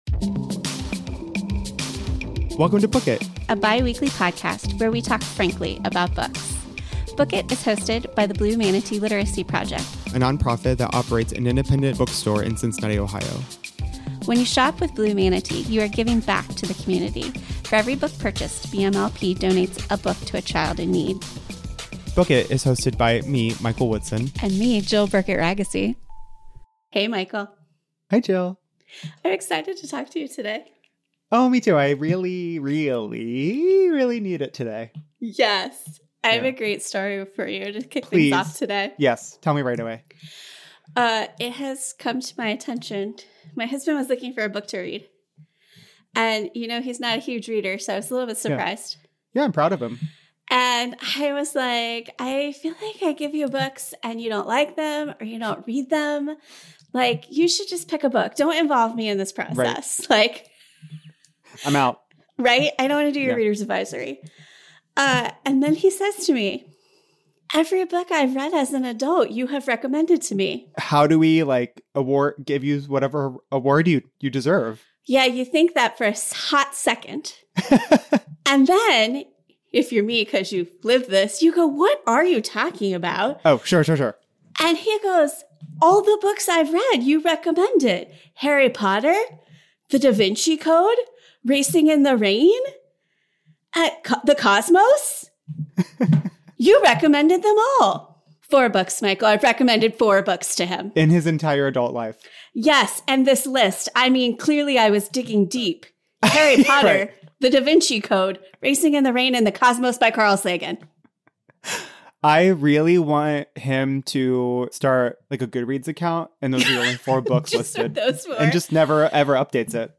In this interview with historian, author, activist and public speaker Blair Imani, we talk about how historical text has evolved, the work she hopes to do, and her personal history that got her to where she is today.